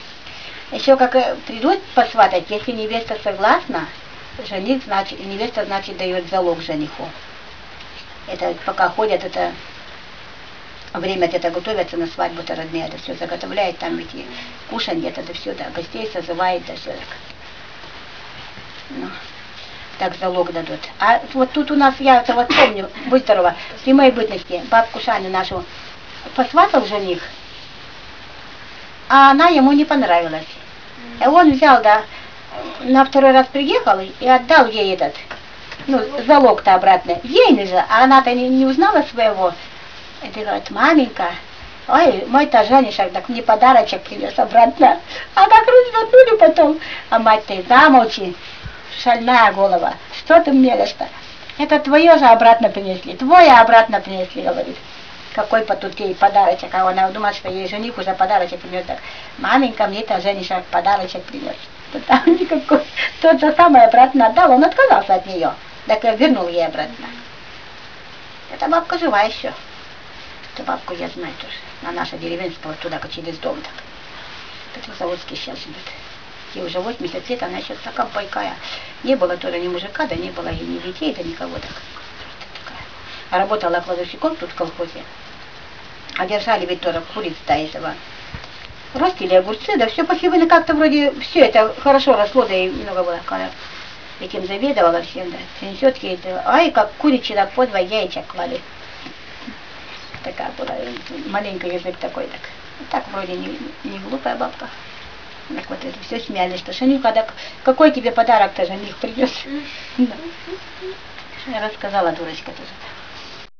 Obrazcy sovremennyh russkih govorov Karelii